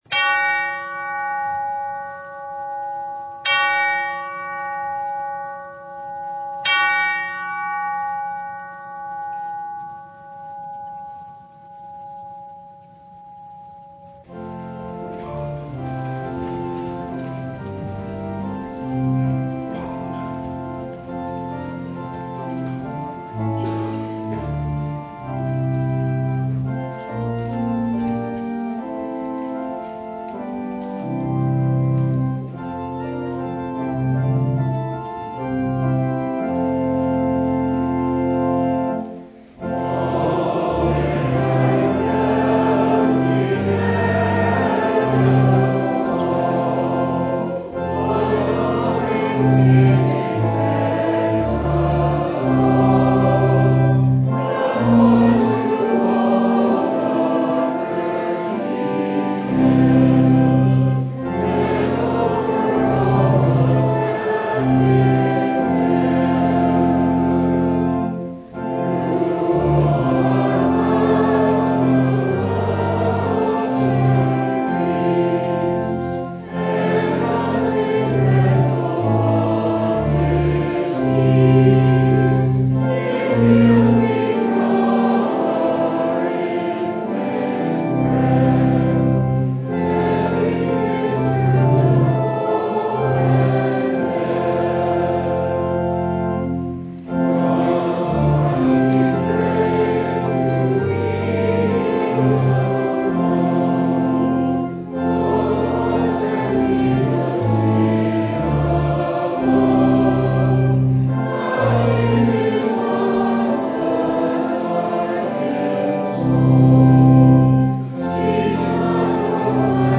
Matthew 25:14-30 Service Type: Sunday Our Lord is not a hard master that we should fear Him.